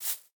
Minecraft Version Minecraft Version 25w18a Latest Release | Latest Snapshot 25w18a / assets / minecraft / sounds / block / bamboo / sapling_hit5.ogg Compare With Compare With Latest Release | Latest Snapshot
sapling_hit5.ogg